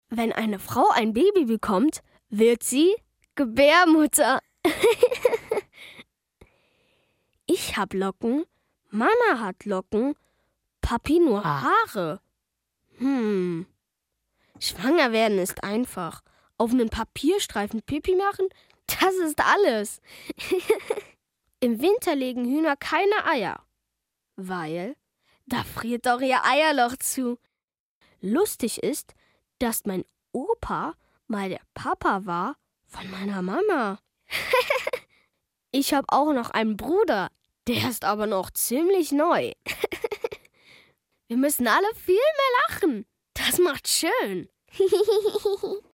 Kinderstimme, Jugendstimme & Sprecherstimme finden leicht gemacht!
kinderstimmen-outtakes-von-kinderstimme-international_www-kinderstimme-eu